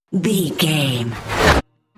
Riser electronic fast
Sound Effects
In-crescendo
Atonal
Fast
futuristic
intense
sci fi